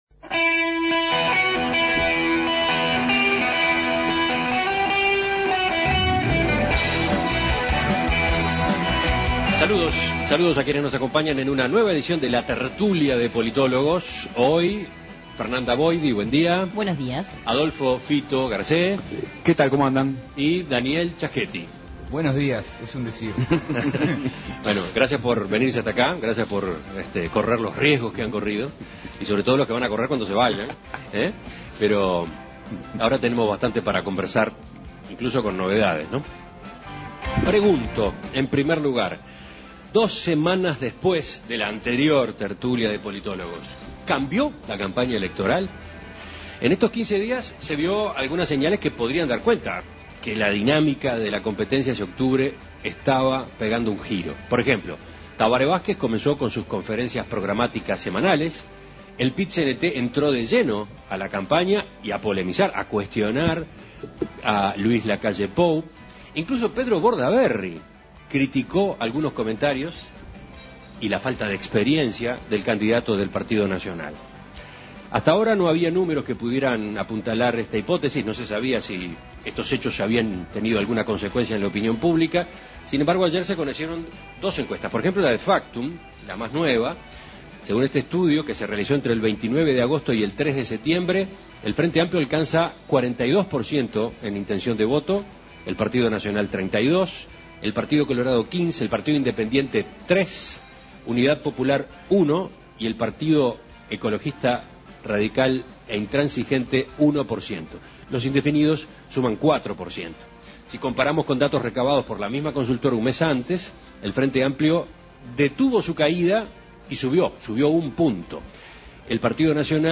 La Tertulia de Politólogos A dos semanas de la última tertulia de politólogos...